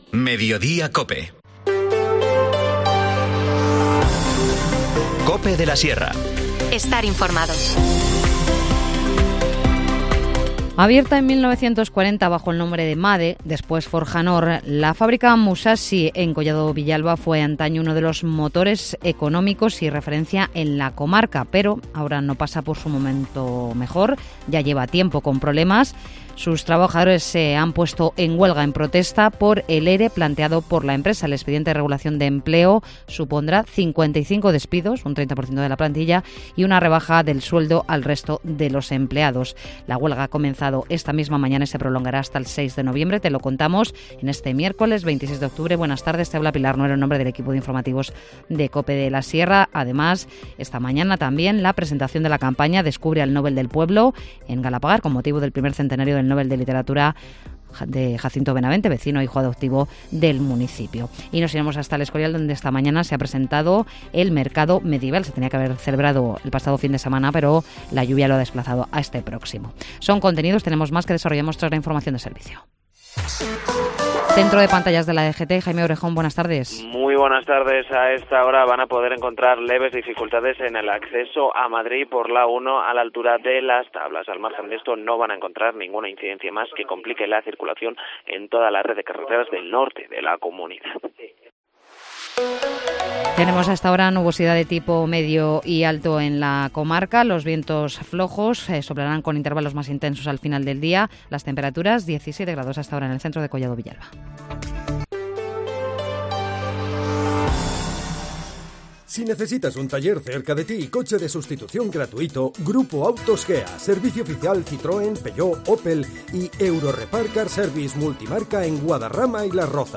Informativo Mediodía 26 octubre